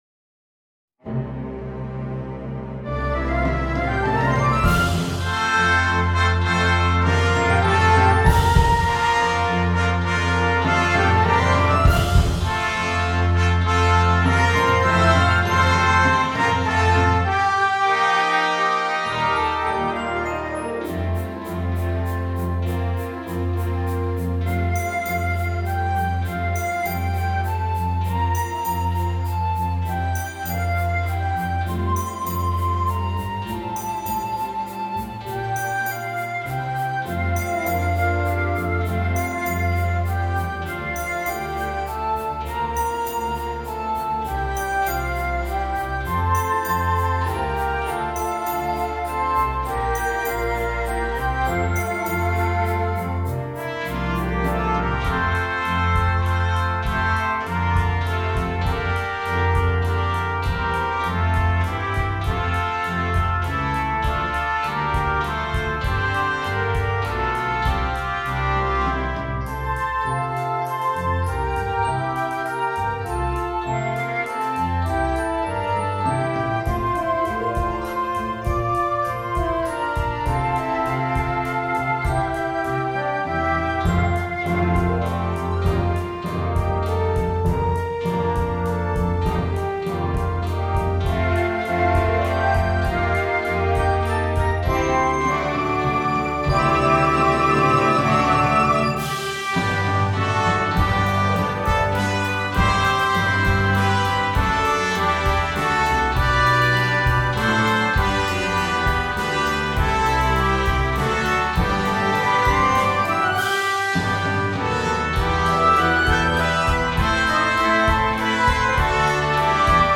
Hymn arrangement for church orchestra and rhythm section
A strong, flowing setting
Arranged for orchestra and rhythm section.